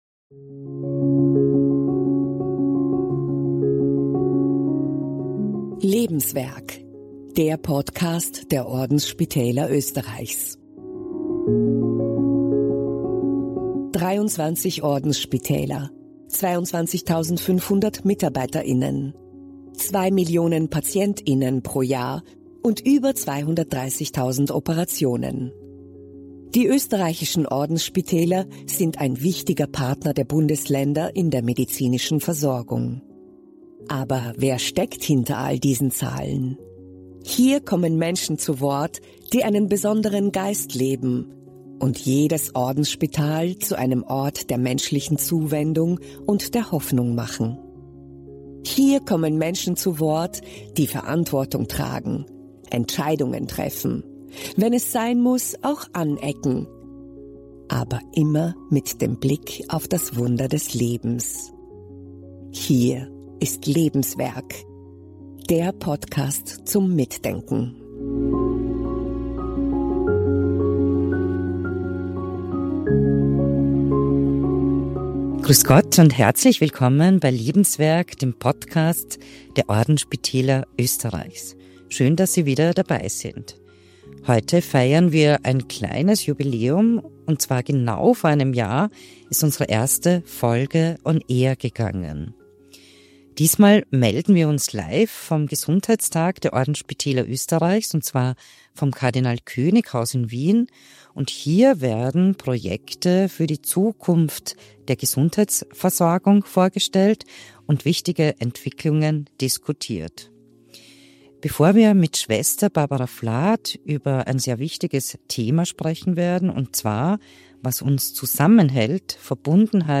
live vom Gesundheitstag 2025 im Kardinal König Haus in Wien